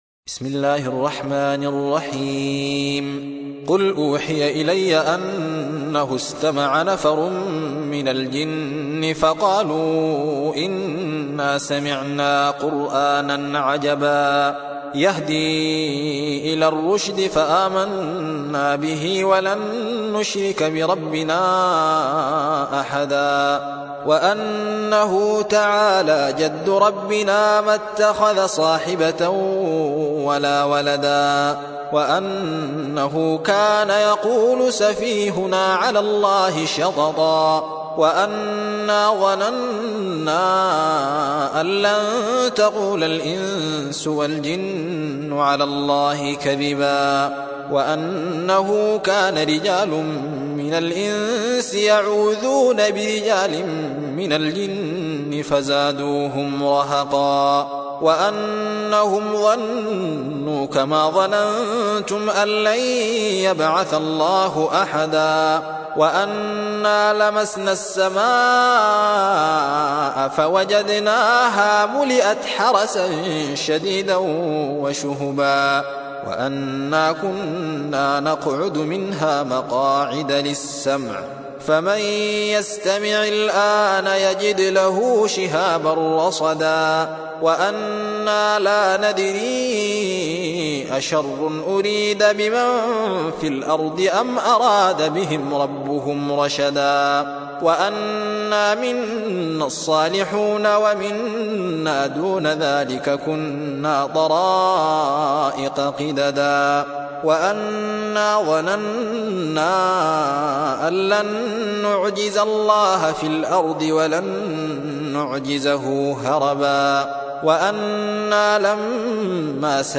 72. سورة الجن / القارئ